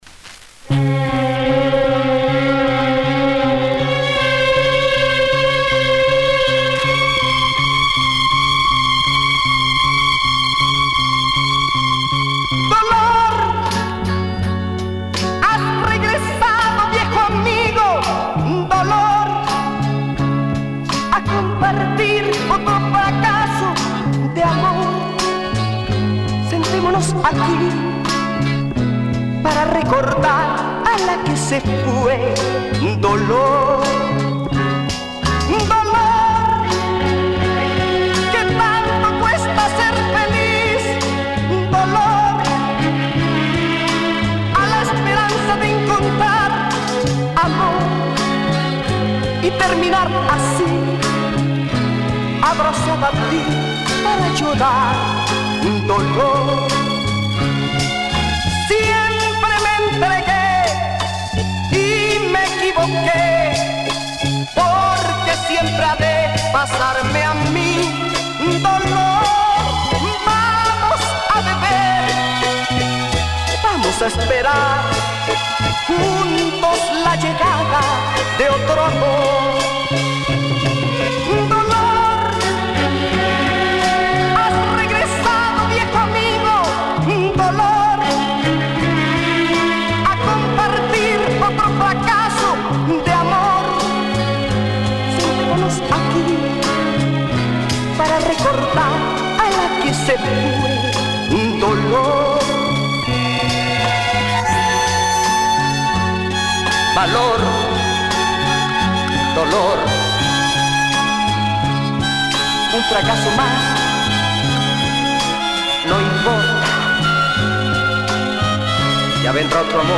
Published September 29, 2010 Boleros y Baladas Comments
over-the-top fuzzed out lyrical romanticism